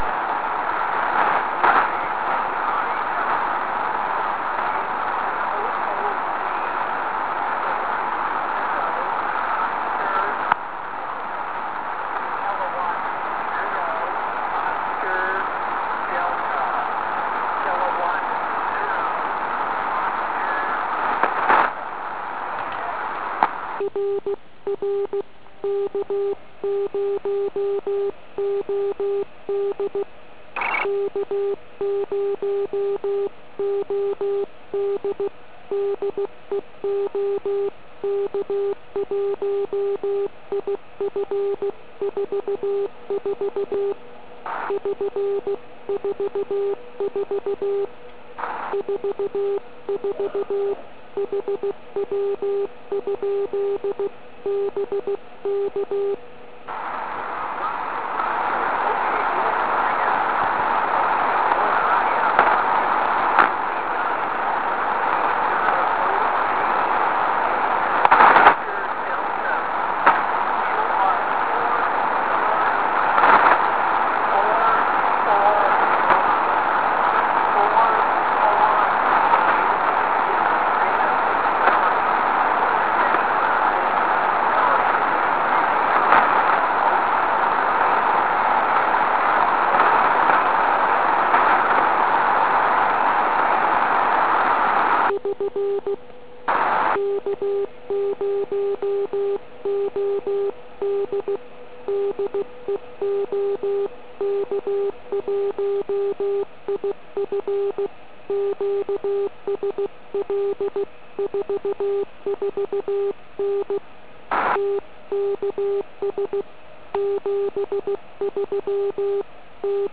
Zde si všimněte, že na komunikaci by to nebylo. Ale perfektní hláskování mi dovolilo bez problémů přečíst značku i report.
Během 2 minut však peak signálu jde dolů a je po žížalách.